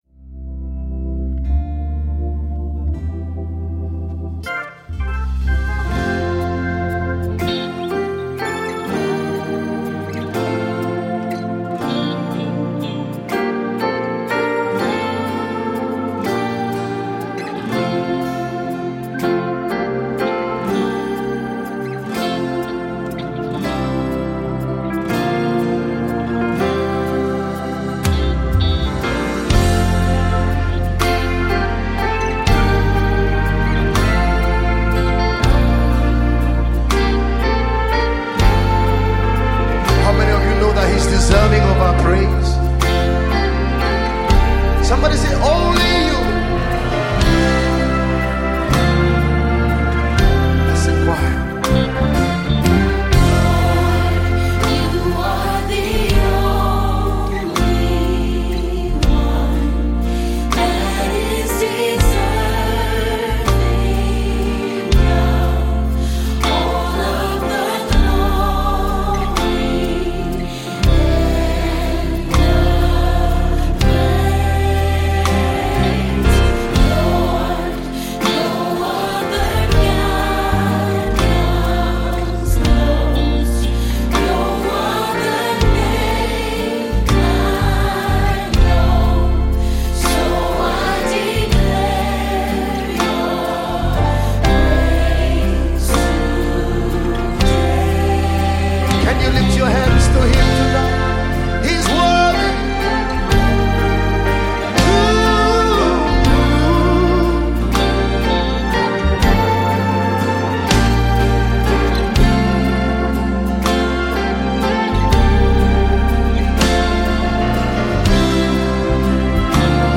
a top-notch Nigerian gospel singer and minister